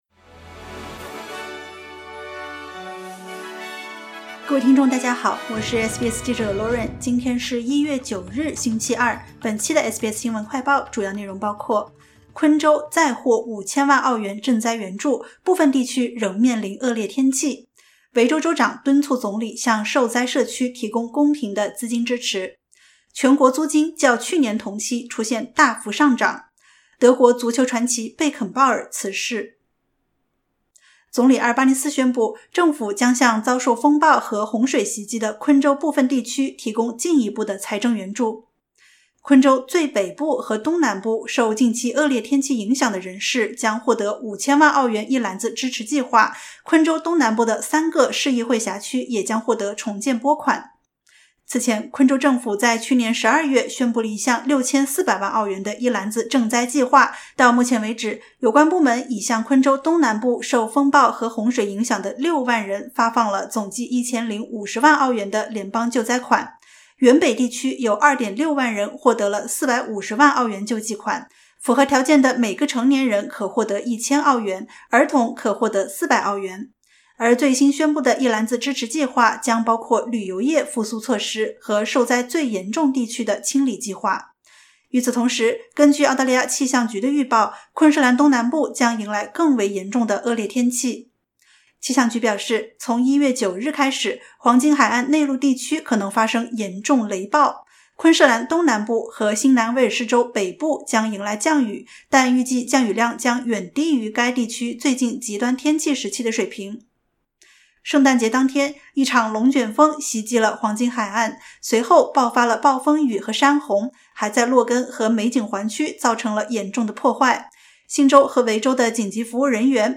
SBS 新闻快报